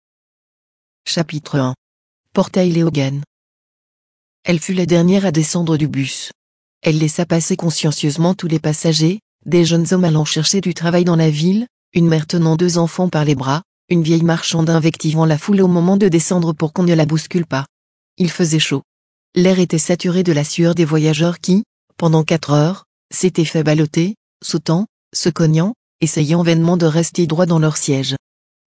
C’est avec la collaboration du groupe Acapela que nous avons récemment acquis un nouvel outil qui va faciliter l'accès de nos membres aux livres : une syntèse vocale au rendu bluffant.
À quoi ressemble la voix de synthèse Manon ?
Manon, c’est une voix de synthèse permettant de lire des livres en version audio.